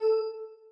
button.ogg